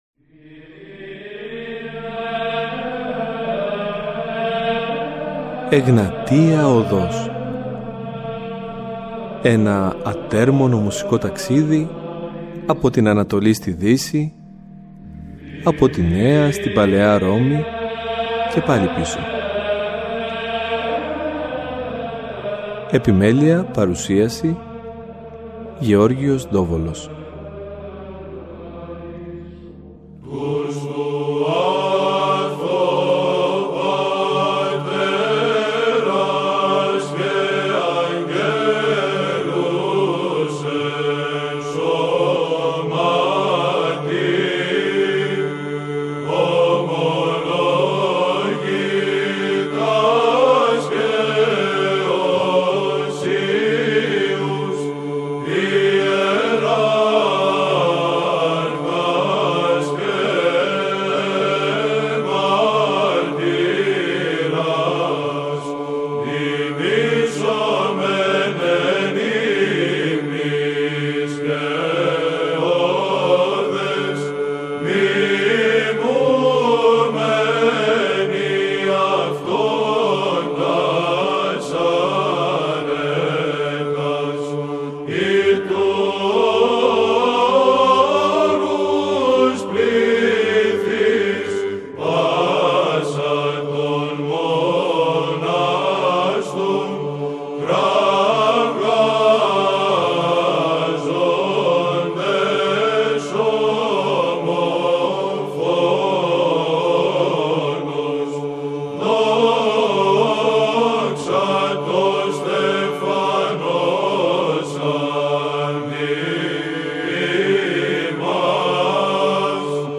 αμφότεροι παραδοσιακοί ερμηνευτές
μάς χαρίζει άλλη μια ξεχωριστή στιγμή ψαλτικής κατάνυξης.